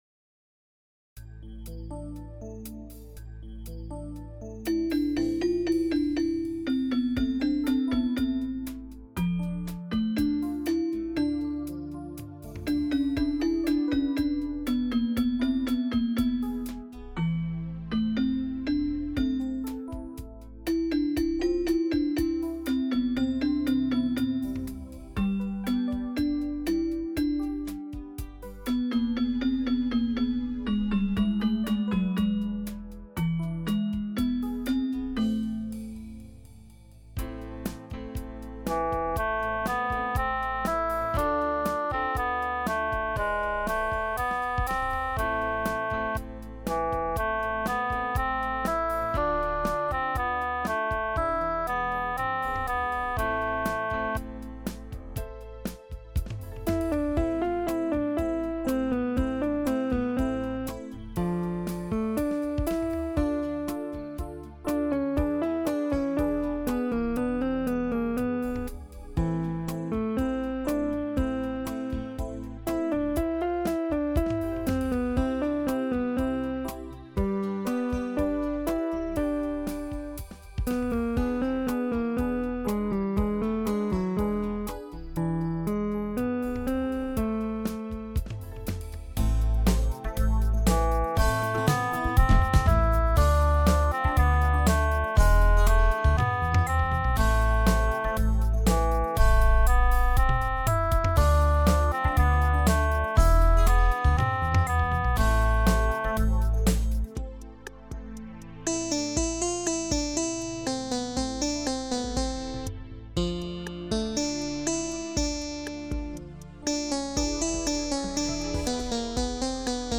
Рубрика: Поезія, Авторська пісня
напрочуд легко читається текст, дуже ніжна пісня! 12